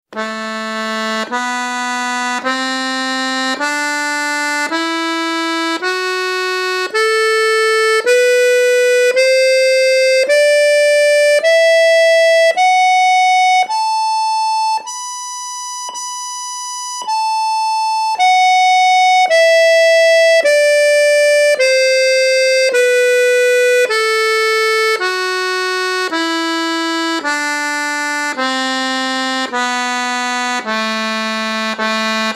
Accordeon diatonique et Musiques Traditionnelles
GAMMES SYNTHETIQUES = gammes incomplétes, les notes en sens inverse du soufflet ne sont pas jouées.
1 - Gamme synthétique de Sol lent tout en tiré